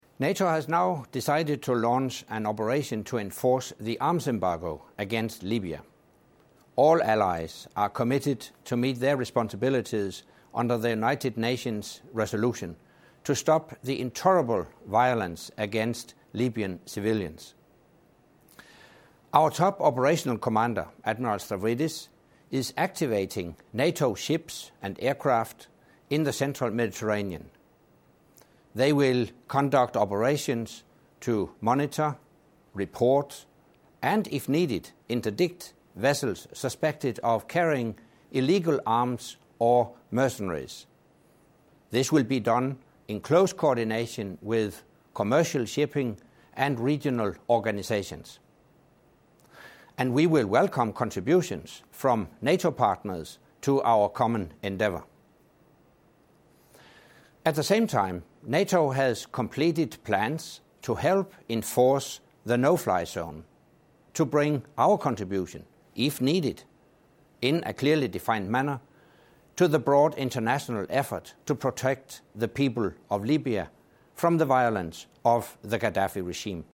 Statement by the NATO Secretary General on Libya arms embargo